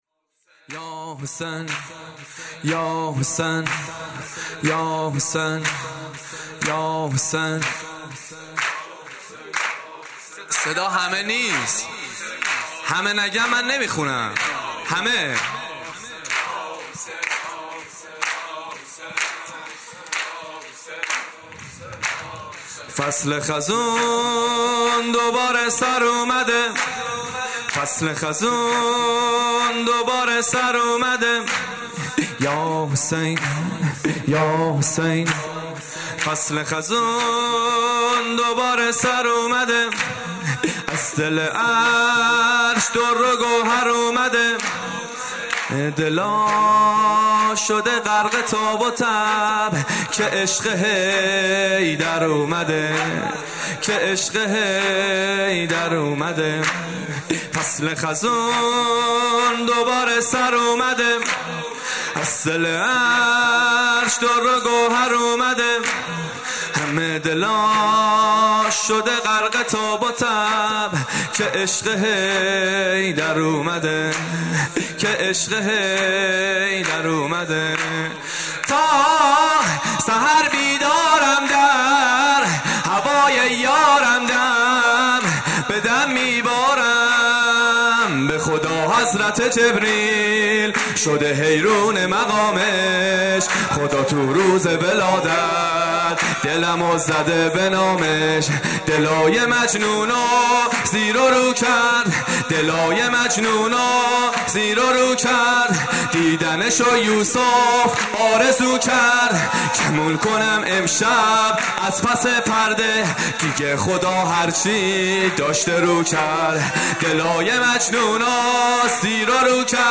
ایام جشن میلاد امام حسین (ع)/هیات عبدالله بن الحسن(ع)
این مراسم با سخنرانی
مرثیه سرایی